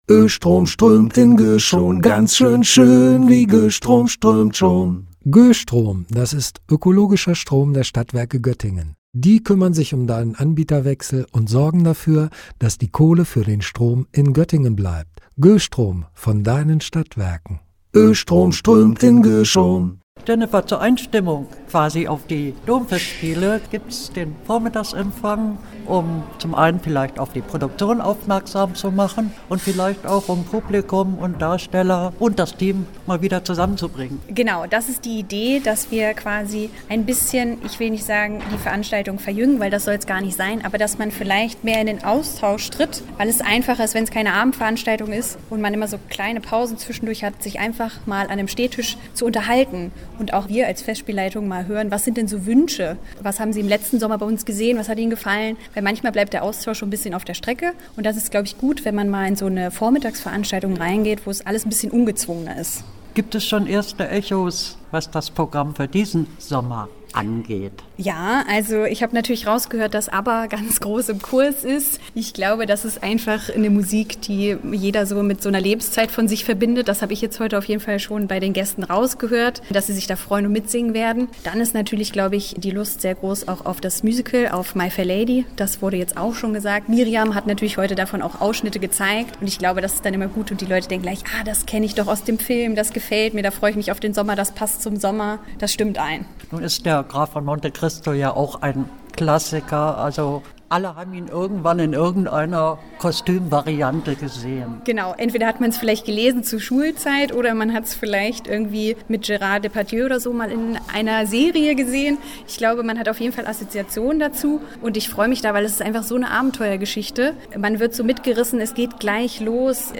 Beiträge > Winter-Matinée mit Ausblick auf die Gandersheimer Domfestspiele - Gespräch